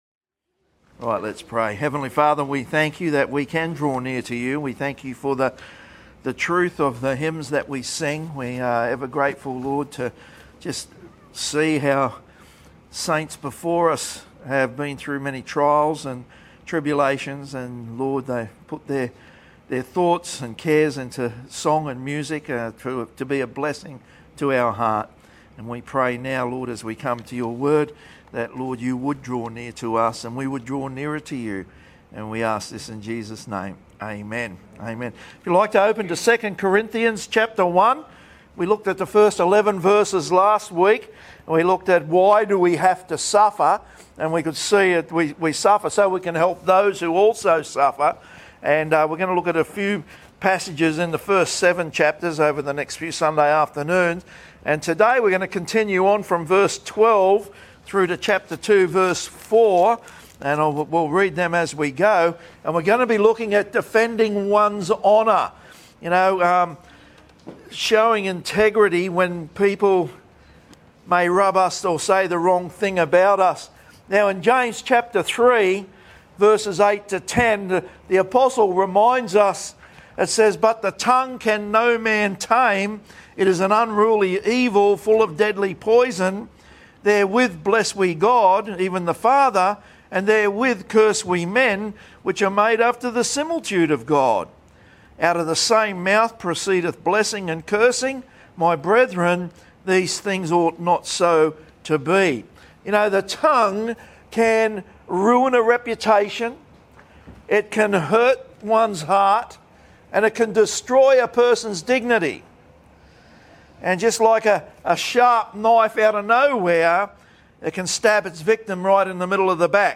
Bible Baptist Church of South East Qld Defending One's Honour Nov 09 2025 | 00:32:45 1x 00:00 / 00:32:45 Subscribe Share Spotify RSS Feed Share Link Embed